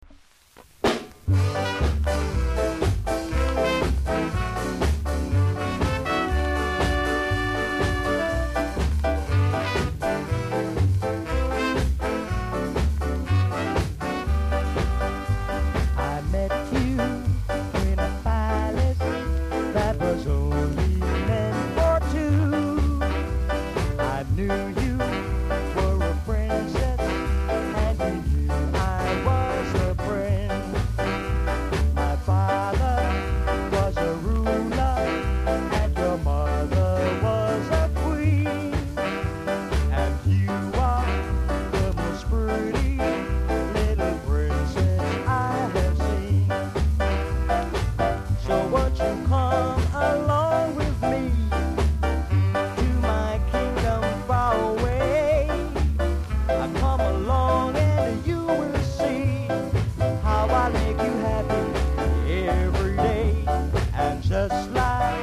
※全体的に薄くノイズあります。小さなチリノイズが少しあります。盤は薄い擦り傷、クモリが少しありますがキレイなほうです。